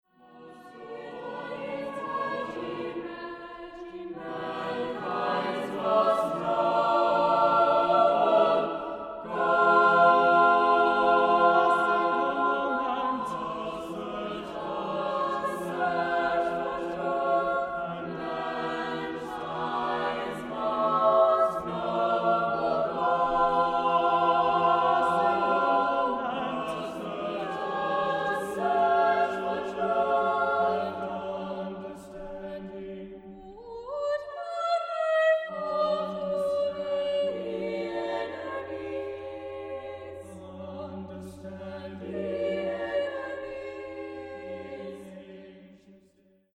Choral